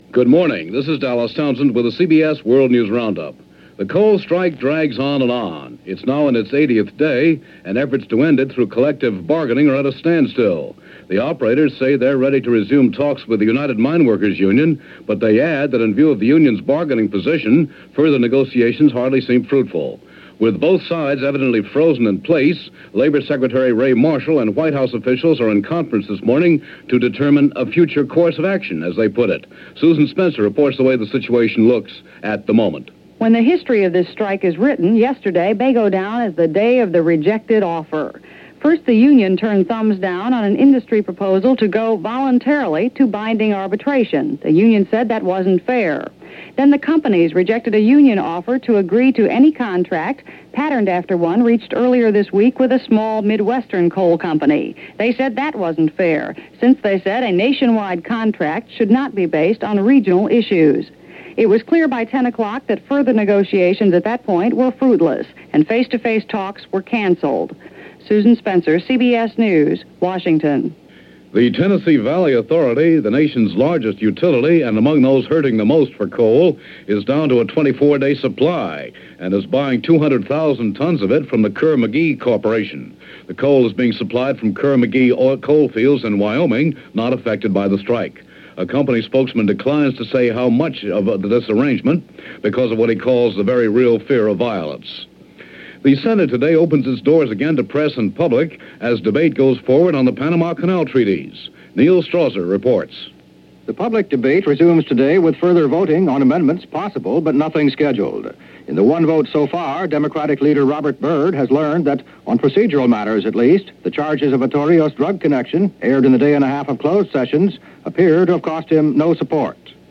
And that’s just a sample of what went on, this February 23, 1978 as reported on The CBS World News Roundup.